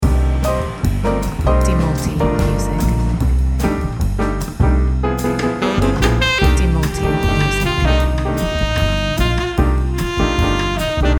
Segar, santai, tapi tetap berkelas.
Musik ini menjaga vibe tetap hidup tanpa mengganggu.